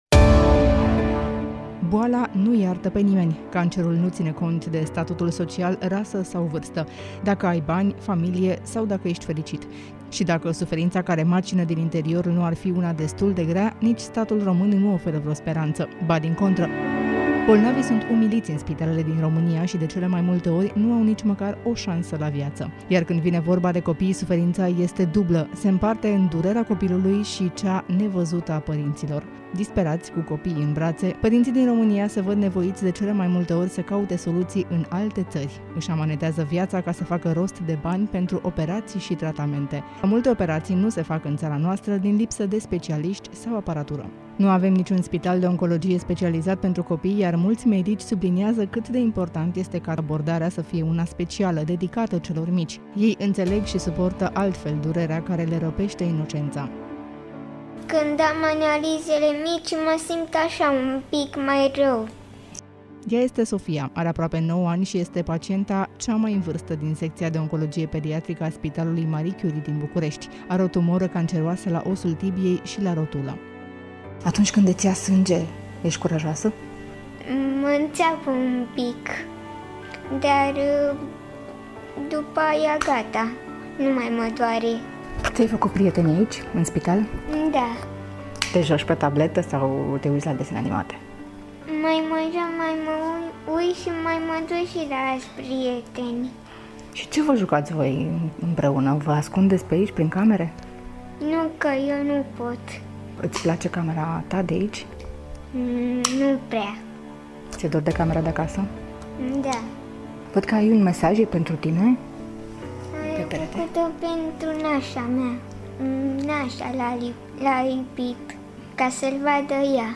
Se aude zgomotul specific al unei constructii şi cu toate acestea pe nimeni nu deranjează.